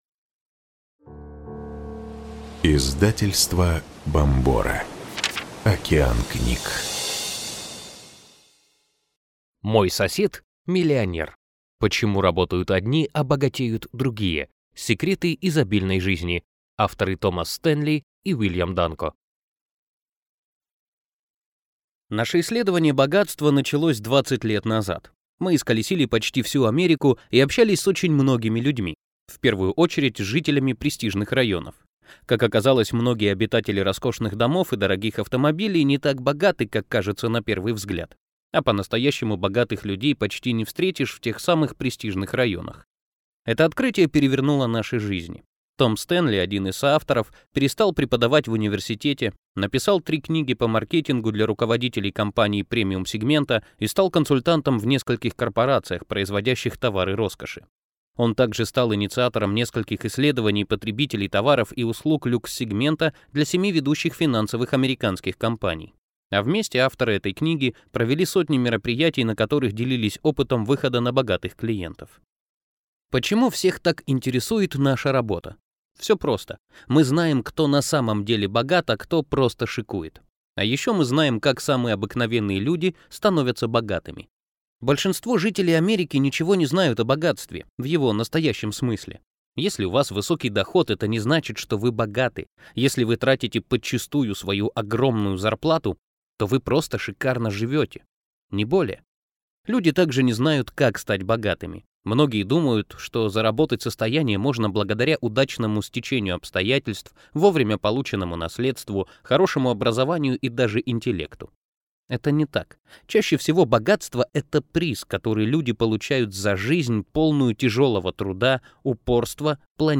Аудиокнига Мой сосед – миллионер. Почему работают одни, а богатеют другие? Секреты изобильной жизни | Библиотека аудиокниг